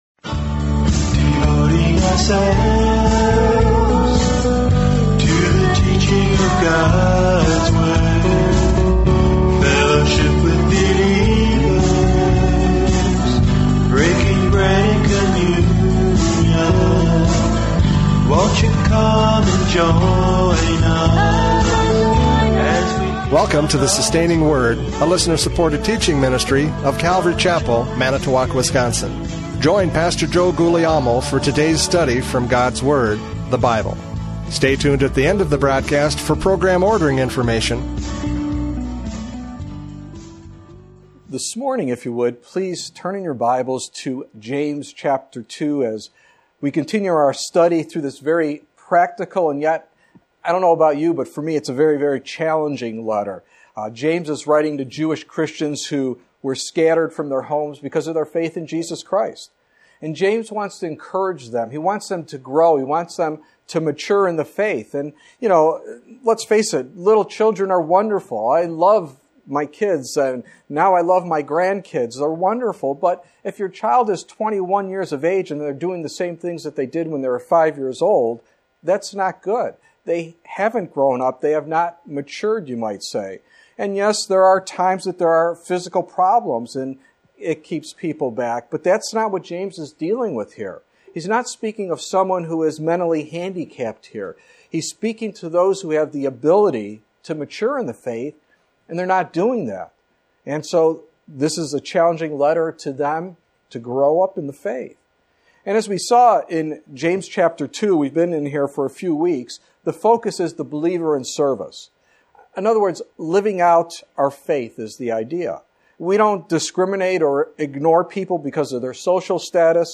James 2:21-26 Service Type: Radio Programs « James 2:14-20 Faith is Proved by Works!